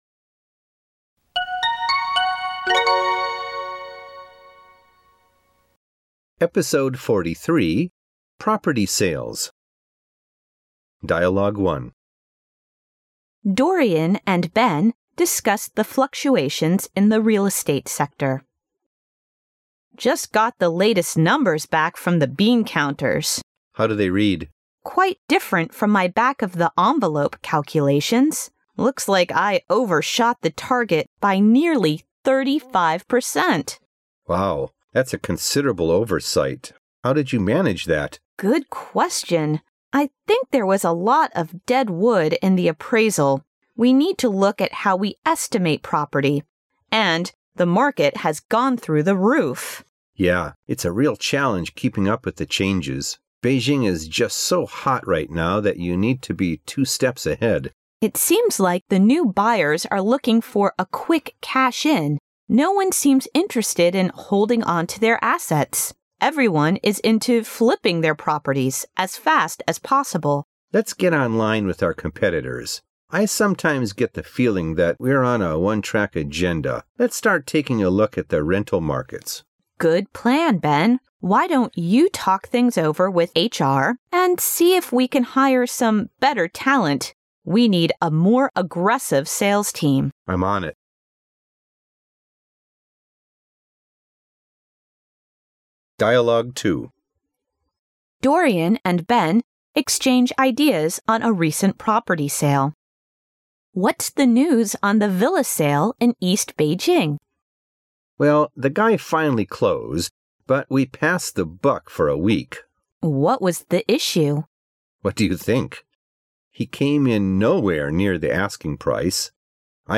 它设计了60个场景，每个场景包含两组对话，内容涉及众多日常生活场景和工作场景，对话语言地道新潮，相关文化背景知识介绍，让你将文化学习与语言学习融为一体，如同亲临现代美国社会，全方位学习地道的英语口语表达。